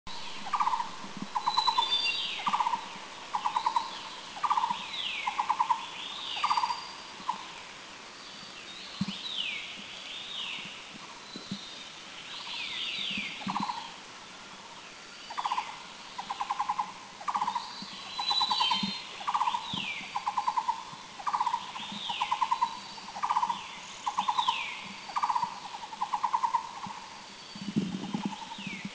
Taiwan Barbet Megalaima nuchalis  Country endemic
B2A_TaiwanBarbetAnmashan410_SDW.mp3